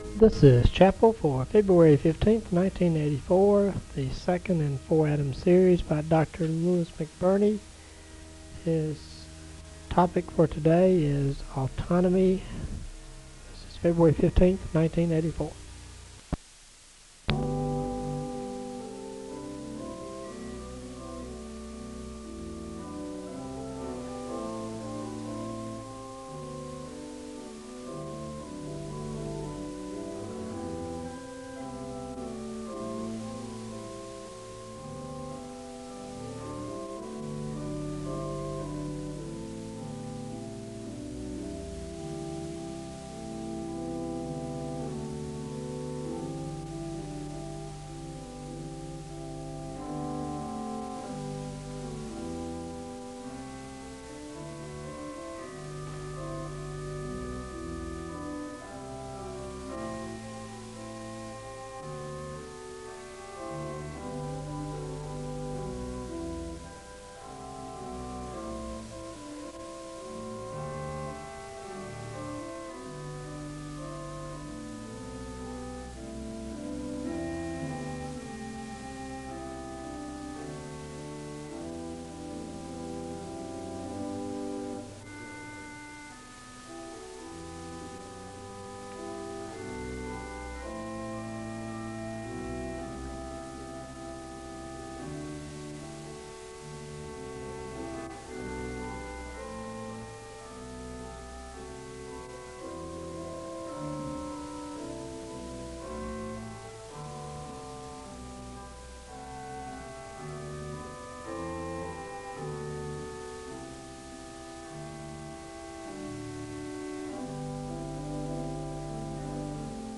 The service begins with organ music (00:00-04:45). The speaker gives a word of prayer (04:46-06:07).
Much of the lecture is a string of points on practical advice for navigating ministry as a pastor (15:57-45:32). The service ends with a word of prayer (45:33-46:08).